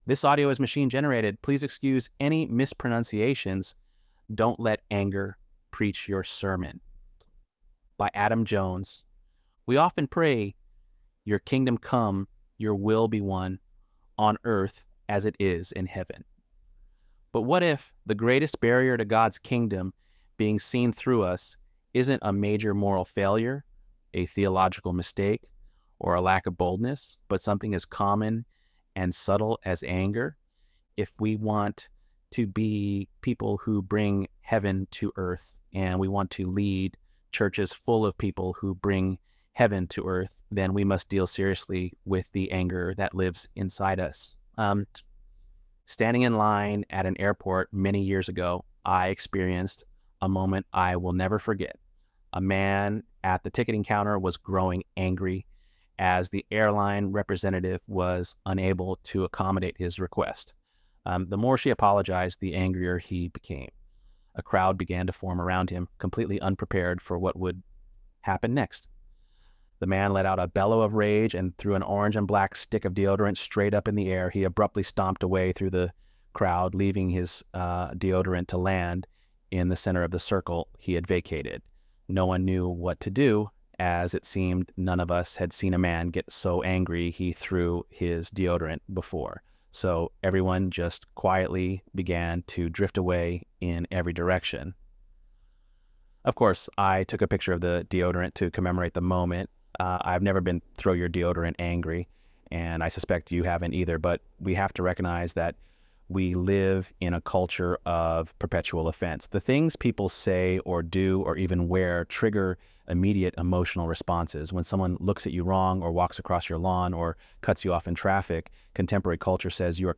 ElevenLabs_8.21.mp3